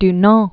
(d-näɴ, dü-), Jean Henri 1828-1910.